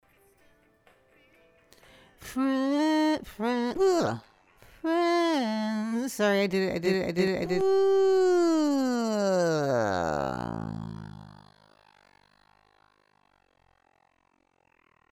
Outtake (the search for a harmony at 6am)